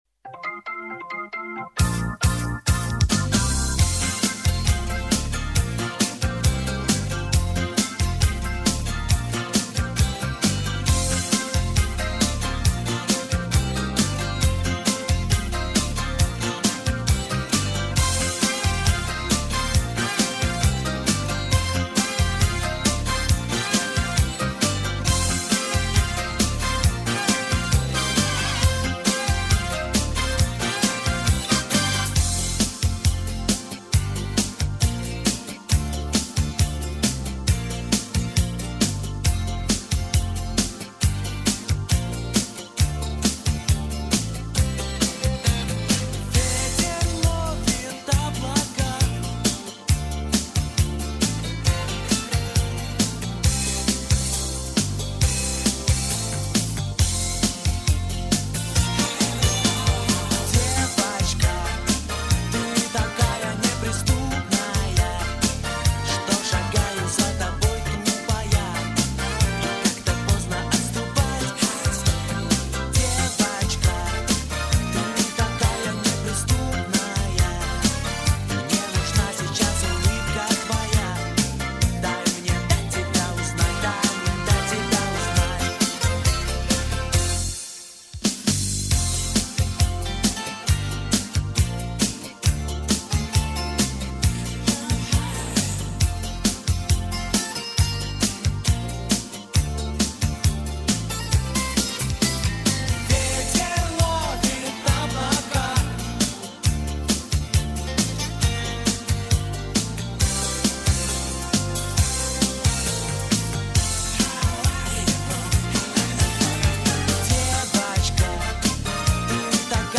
Пойте караоке
минусовка версия 44234